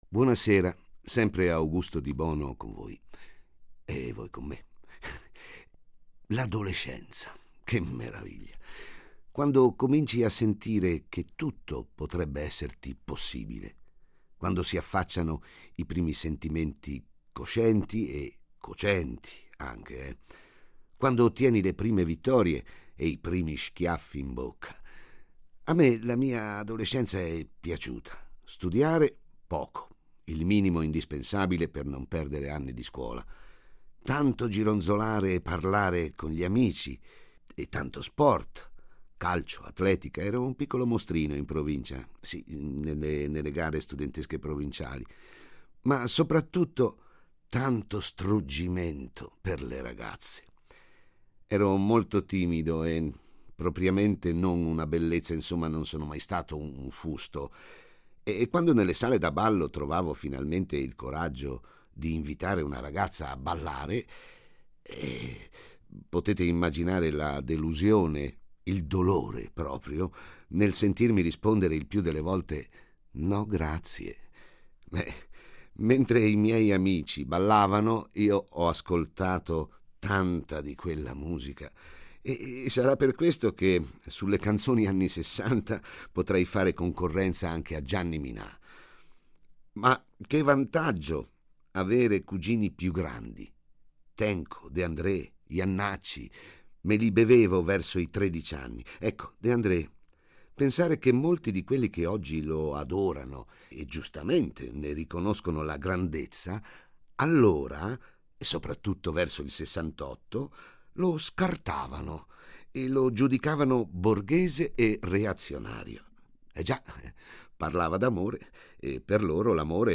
Dedichiamo il periodo natalizio di Colpo di poesia all’Amore attingendo alla serie che invitava gli attori della Fiction radiofonica a raccontarsi e raccontare una poesia.